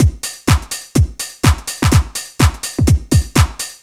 Index of /musicradar/retro-house-samples/Drum Loops
Beat 20 Full (125BPM).wav